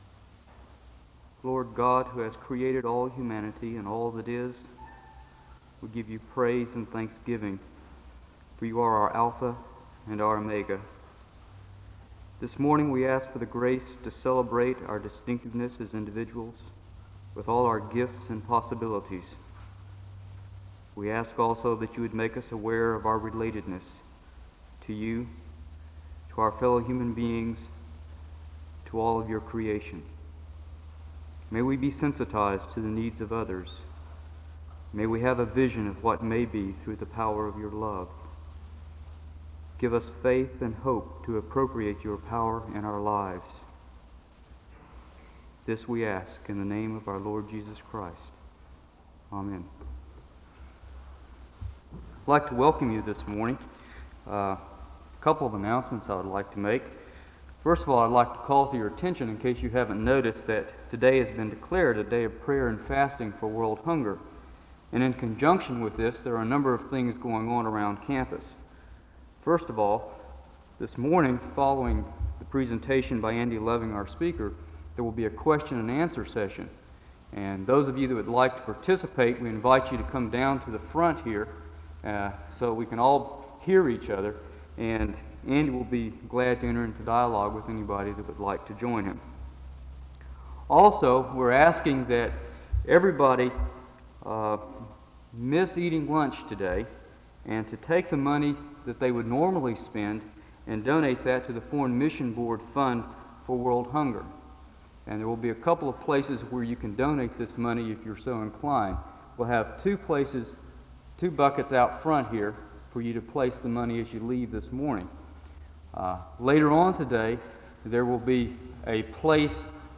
The service begins with a word of prayer (00:00-00:50).
The choir sings a song of worship (04:38-08:37).
Location Wake Forest (N.C.) Resource type Audio Citation Archives and Special Collections, Library at Southeastern, Southeastern Baptist Theological Seminary, Wake Forest, NC.